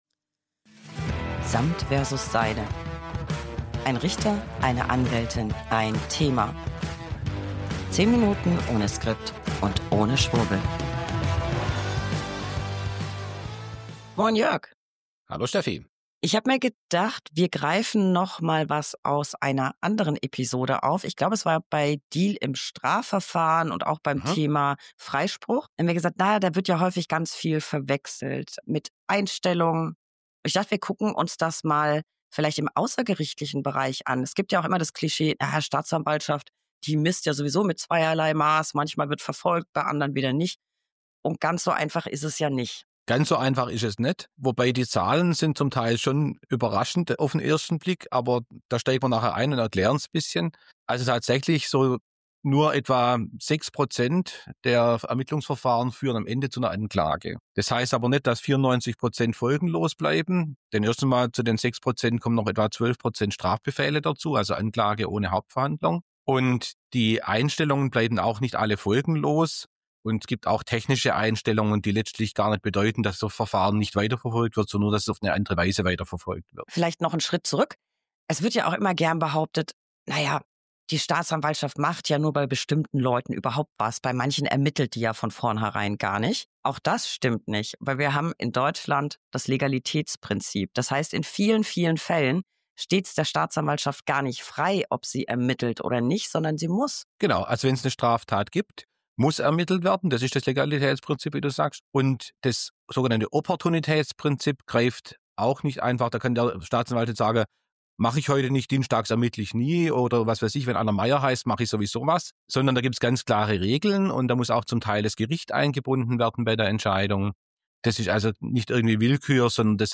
1 Anwältin + 1 Richter + 1 Thema. 10 Minuten ohne Skript und ohne Schwurbel. Im Ermittlungsverfahren gibt es keinen Freispruch. Verfahren können aber aus den unterschiedlichsten Gründen eingestellt werden.